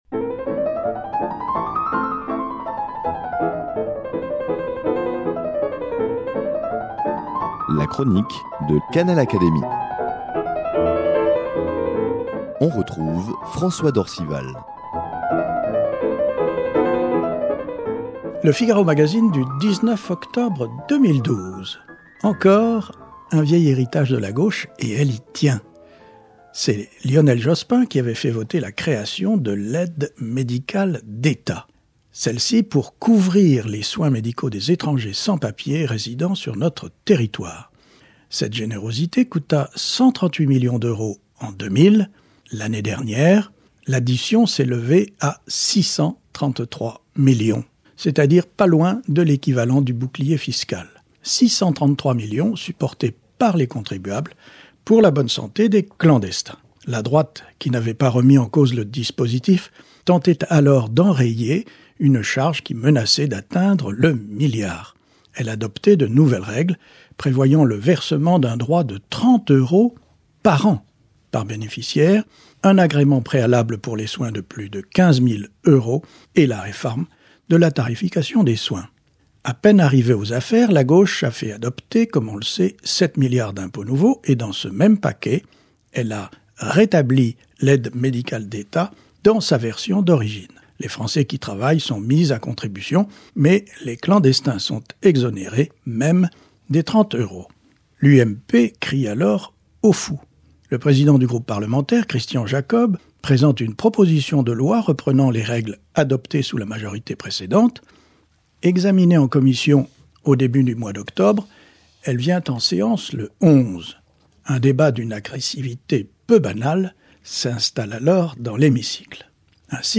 Toujours d’actualité... la chronique de François d’Orcival de l’Académie des sciences morales et politiques